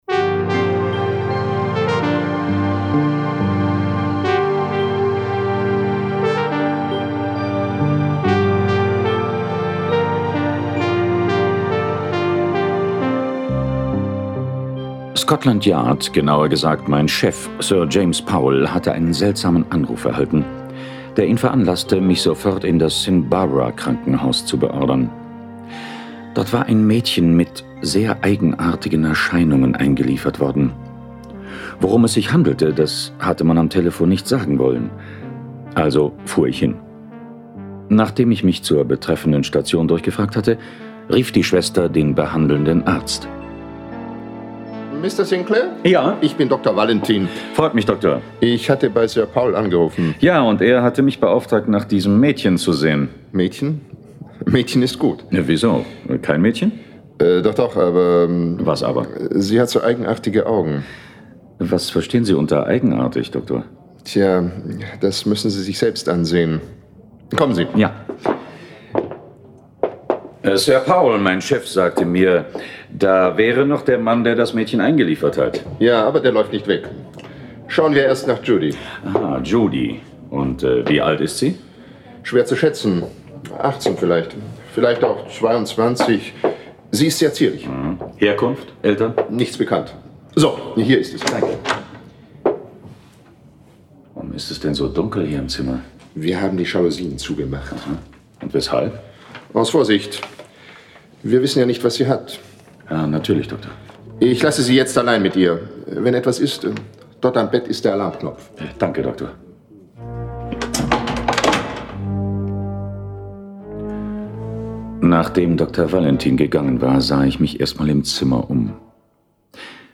John Sinclair Tonstudio Braun - Folge 55 Judys Spinnenfluch. Jason Dark (Autor) diverse (Sprecher) Audio-CD 2017 | 1.